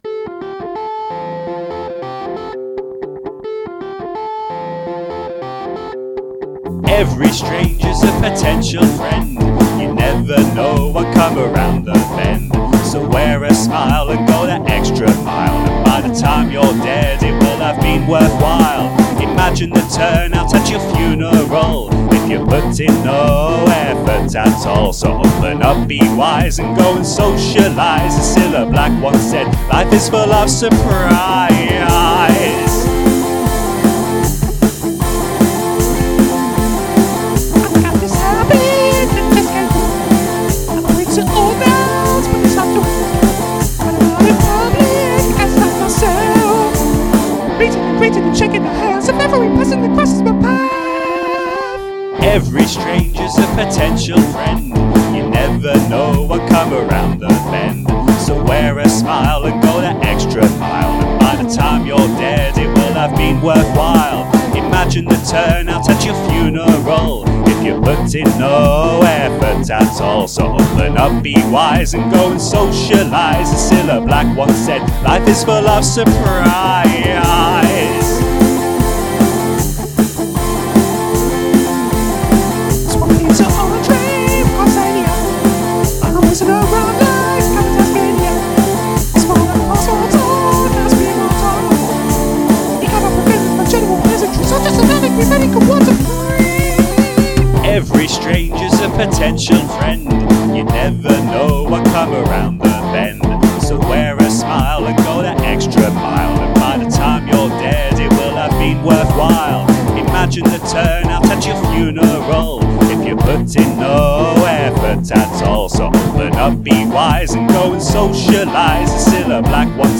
The chorus even get's a key change just to keep it going.